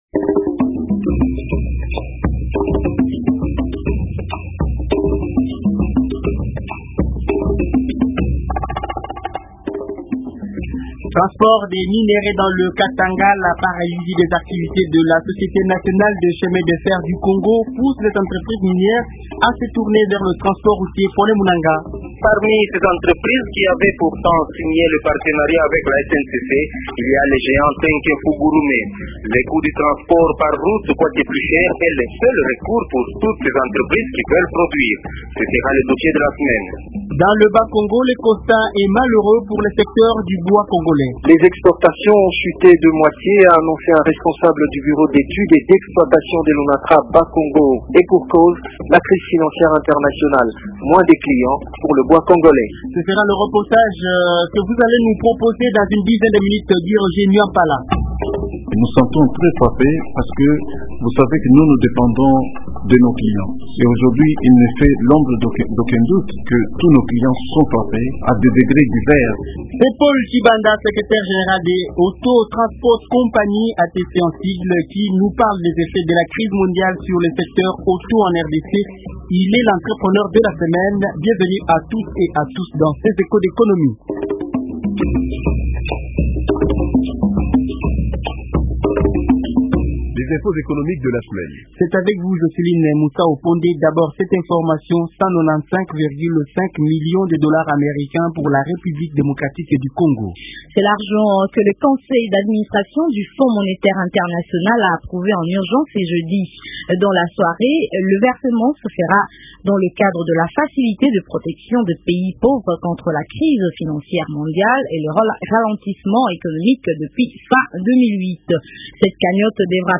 C’est le dossier au coeur du magazine économique de Radio Okapi. Le reportage sur la baisse des exportations de bois dans le Bas Congo vient se joindre au concert des nouvelles économiques du pays.